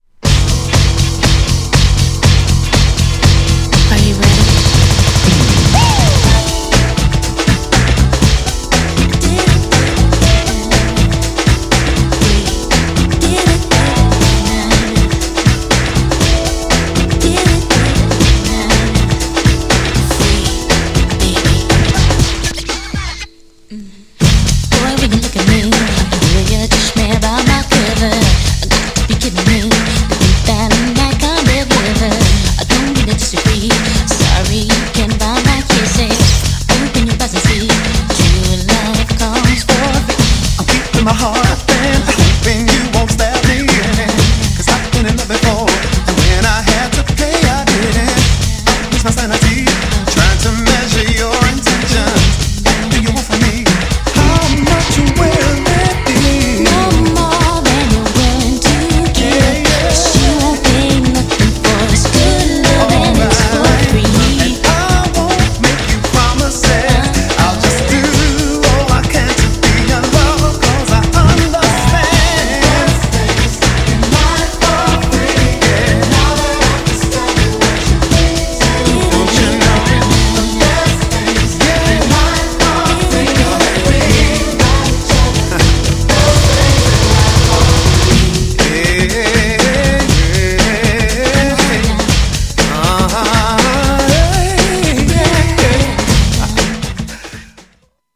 抜群の掛け合いを聴かせる
GENRE House
BPM 121〜125BPM